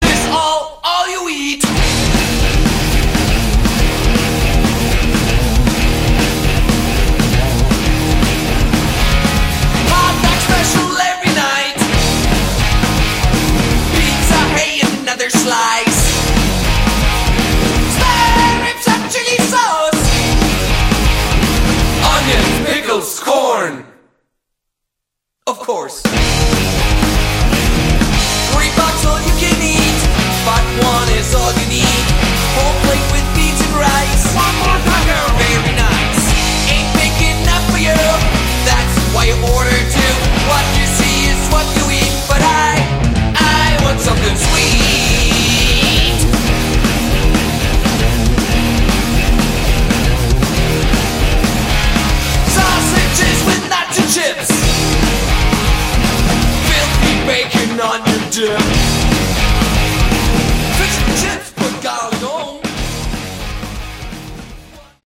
Category: Melodic Metal
vocals
guitar
drums
bass